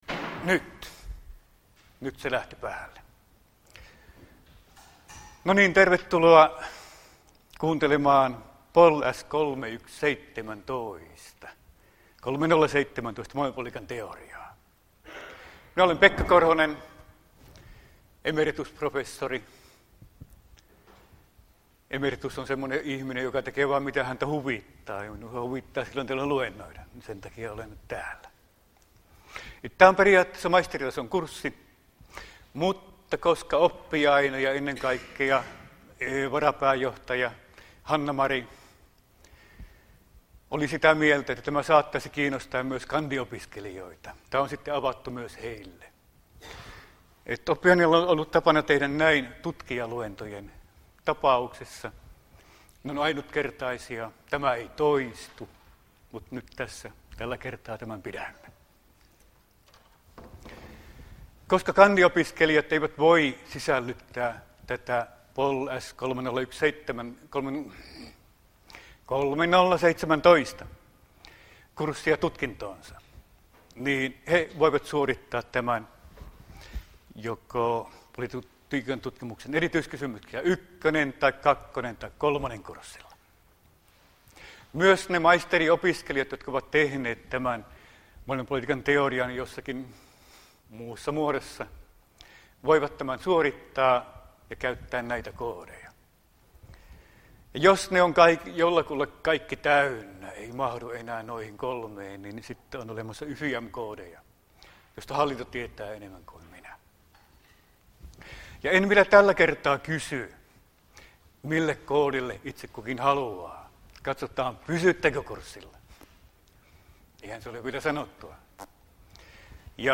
POLS3017 Luento 1 — Moniviestin